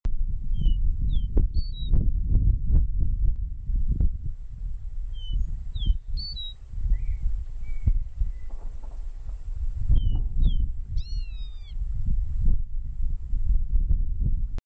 Song male Arroyos y Esteros km74, Departamento Cordillera
Sporophilaruficollisimmmale.mp3